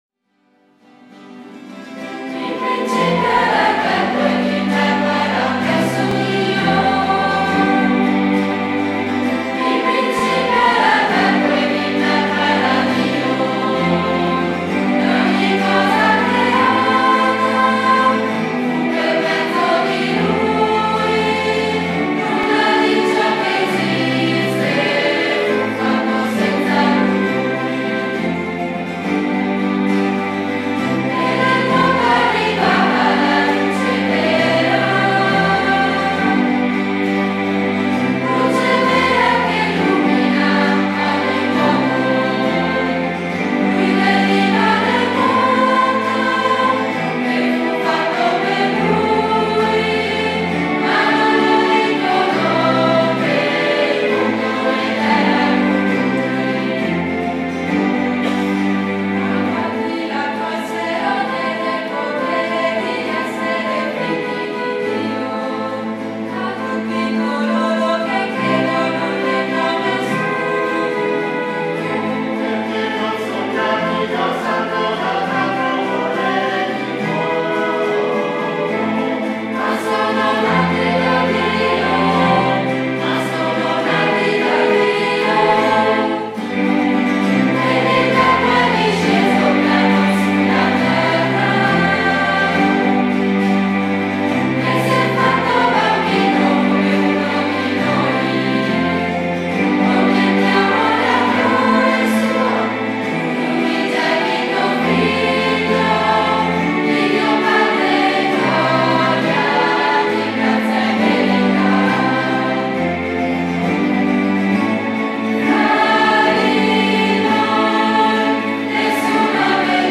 Piccolo coro
E’ il 1996 quando due chitarre si ritrovano in oratorio a Madonna della Salute (di Maser) con un gruppo di bambini per unire musica e parole in preghiera.
E’ così che, col tempo, si cominciò ad abbellire i canti con altre voci, nuove chitarre e un flauto traverso.
A chi è rivolto: Bambini e ragazzi a partire dai 6 anni.